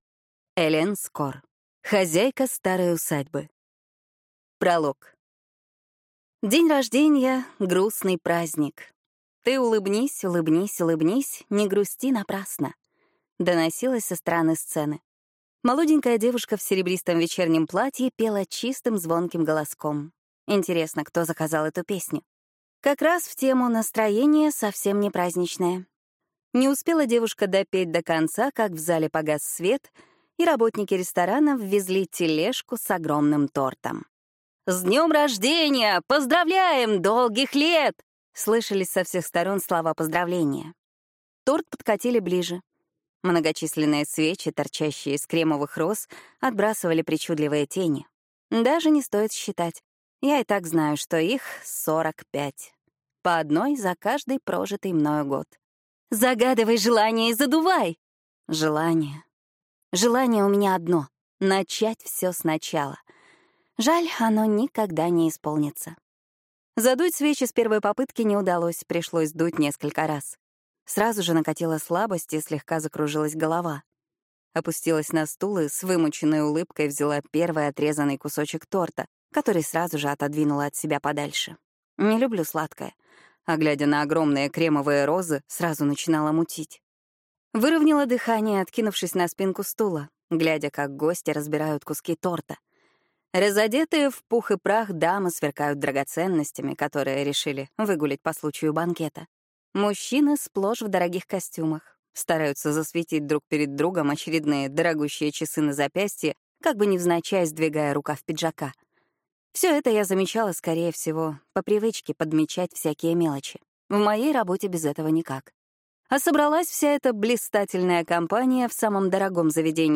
Аудиокнига Хозяйка старой усадьбы | Библиотека аудиокниг